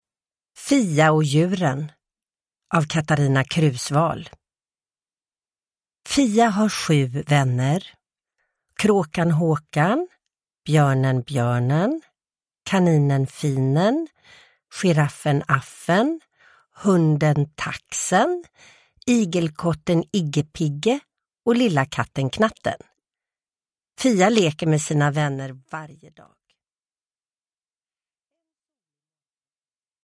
Fia och djuren – Ljudbok – Laddas ner
Uppläsare: Sissela Kyle